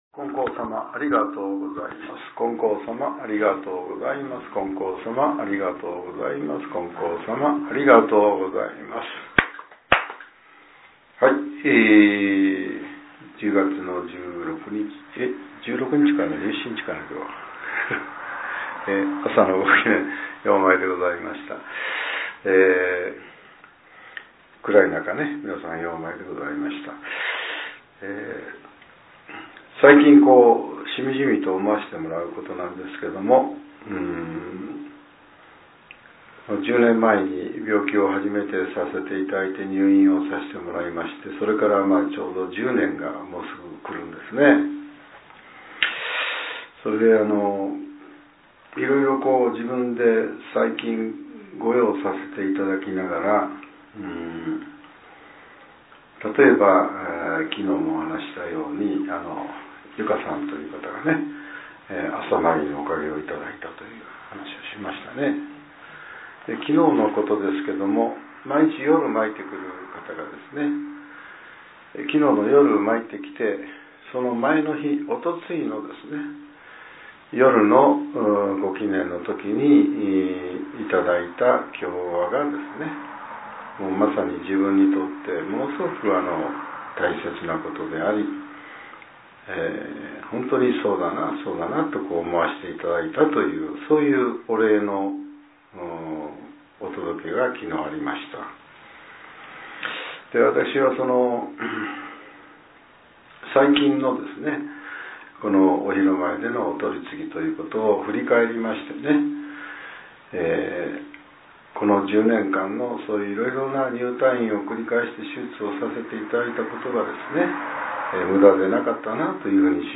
令和７年１０月１７日（朝）のお話が、音声ブログとして更新させれています。